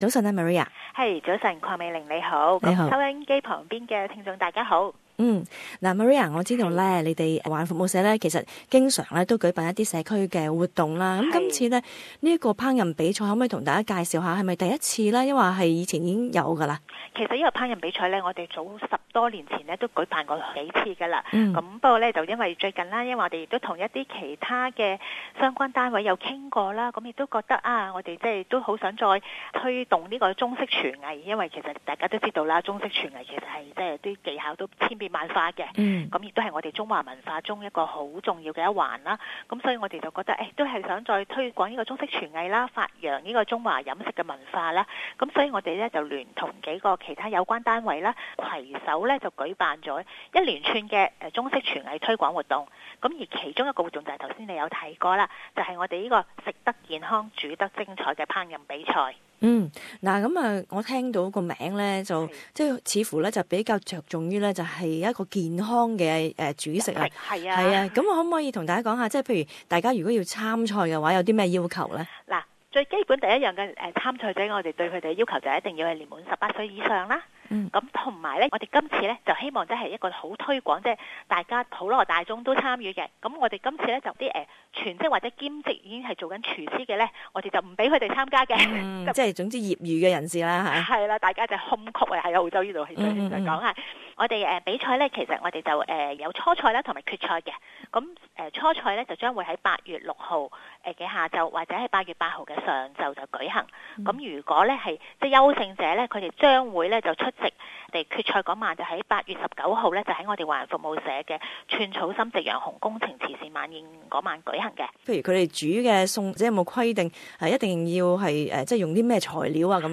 【社區專訪】烹飪比賽鼓勵健康飲食